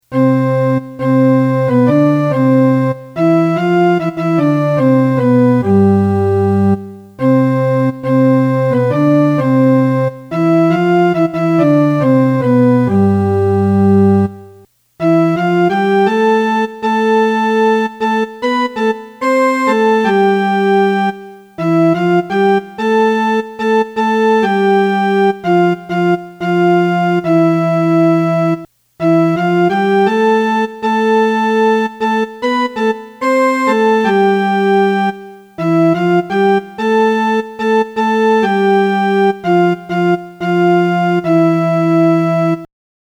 gran-dio-del-cielo-melody.mp3